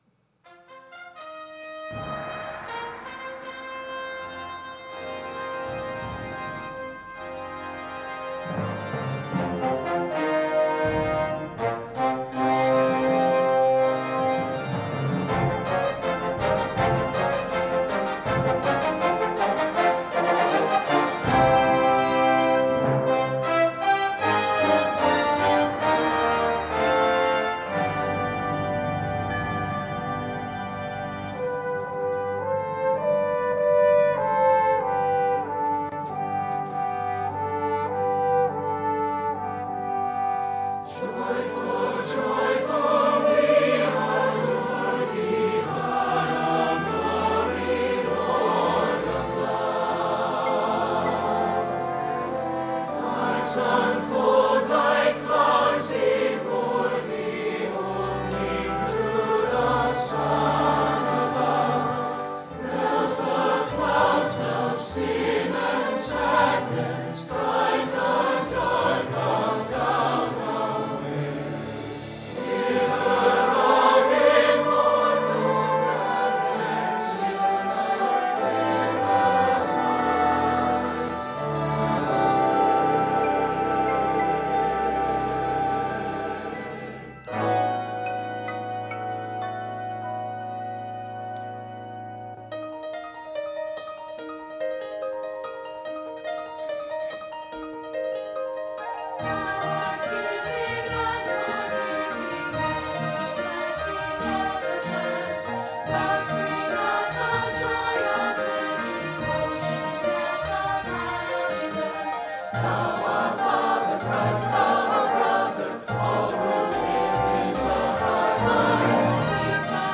Sunday Morning Music
Celebration Choir